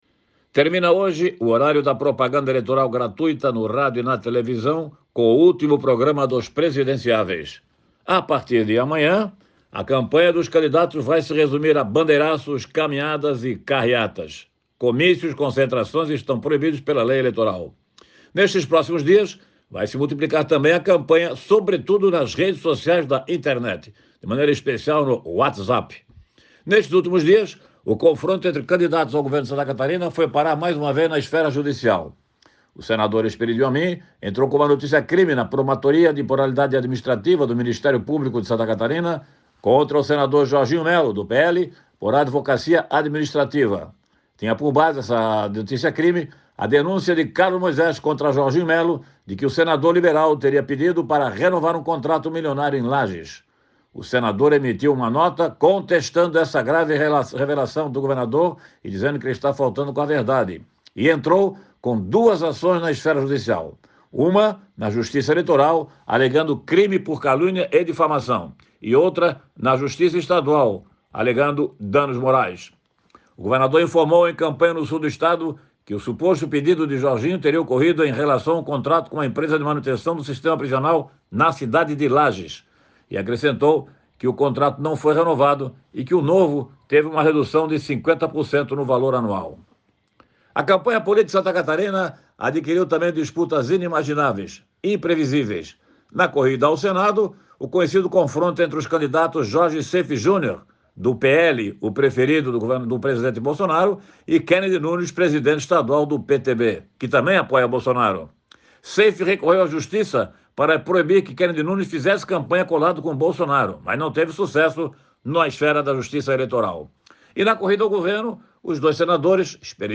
Jornalista destaca que atos de campanha devem seguir nas redes sociais até o dia do pleito